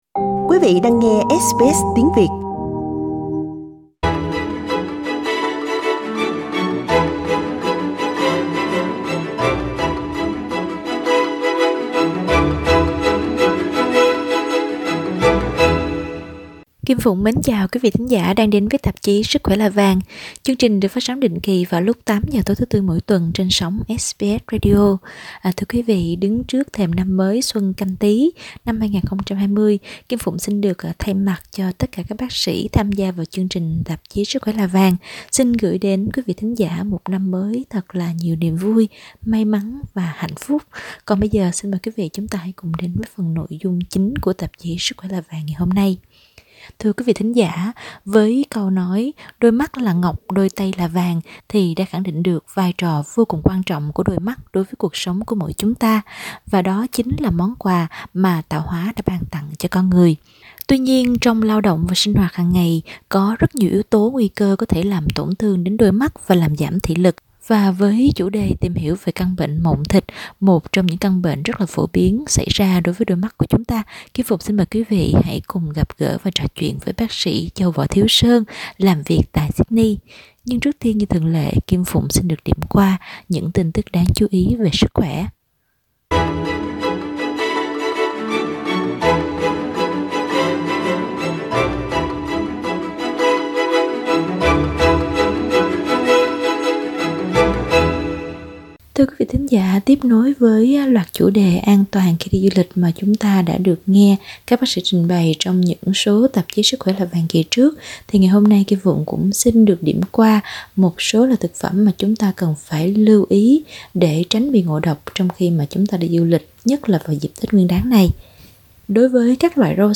chuyên khoa phẫu thuật mắt trình bày chi tiết hơn về cách phòng ngừa và điều trị.